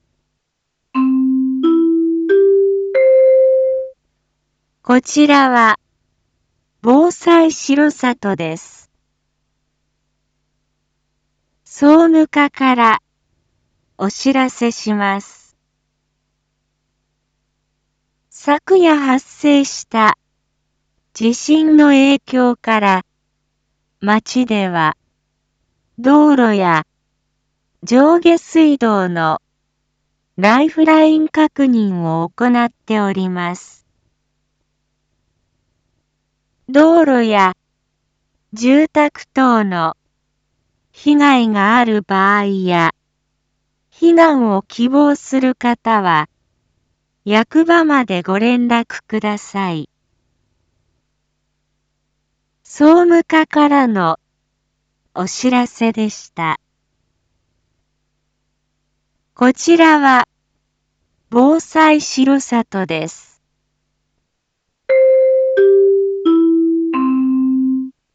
一般放送情報
Back Home 一般放送情報 音声放送 再生 一般放送情報 登録日時：2022-03-17 07:01:09 タイトル：R4.3.17 7時放送 地震発生確認 インフォメーション：こちらは、防災しろさとです。